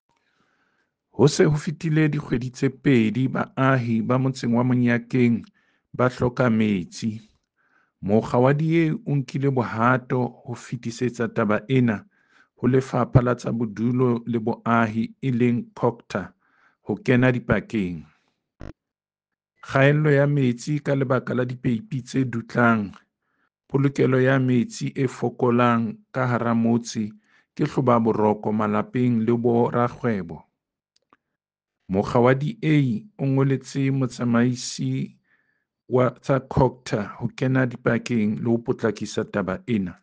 Sesotho soundbites by David Masoeu MPL.